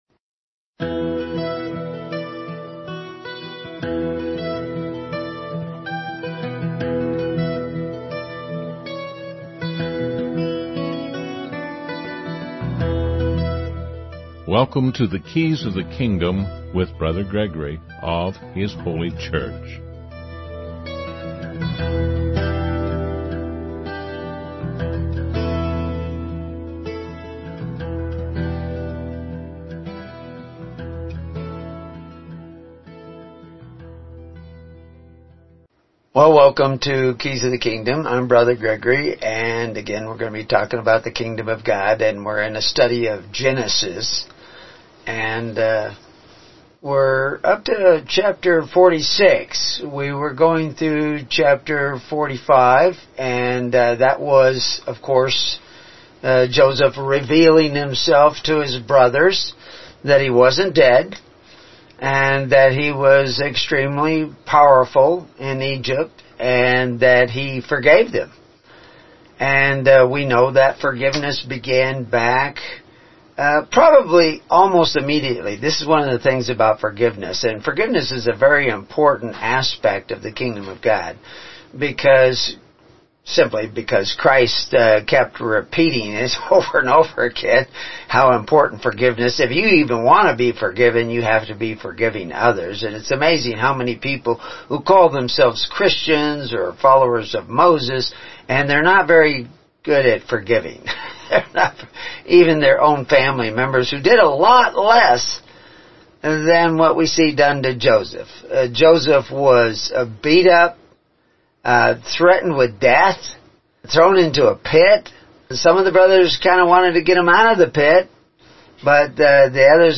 "Keys of the Kingdom" is broadcast weekly (Saturday mornings - 10AM Central Time) on First Amendment Radio.